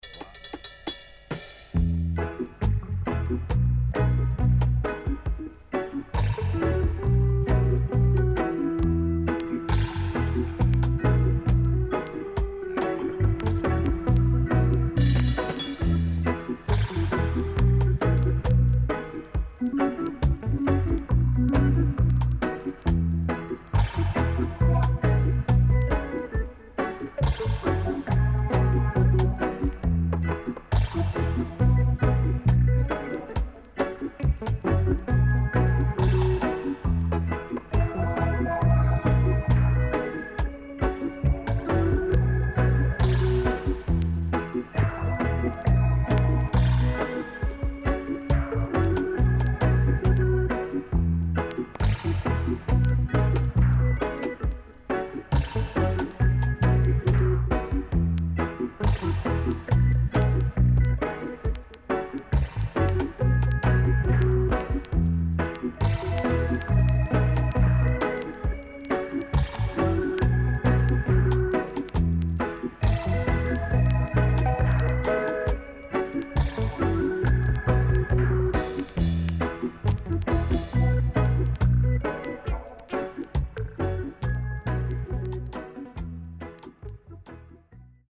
SIDE A:序盤プレス起因の凸がありノイズ入ります。所々チリノイズ、プチノイズ入ります。